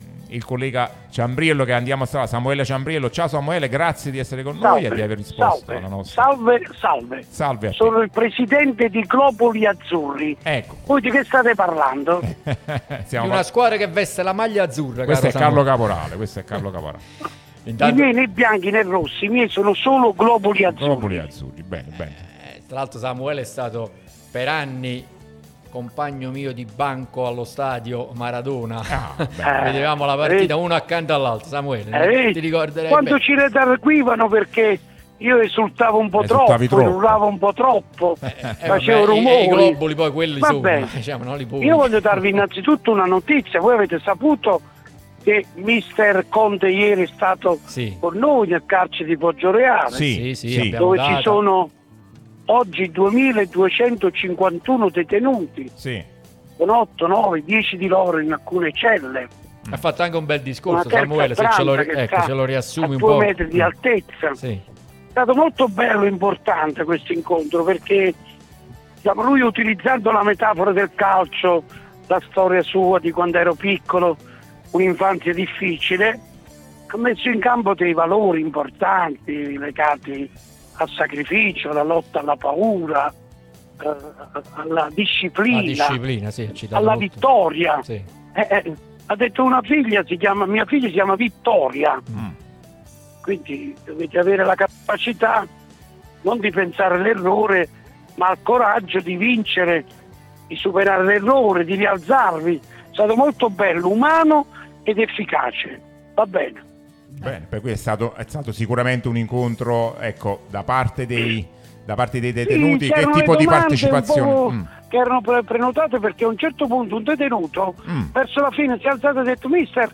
Samuele Ciambriello, giornalista e garante dei detenuti in Campania, è intervenuto sulla nostra Radio Tutto Napoli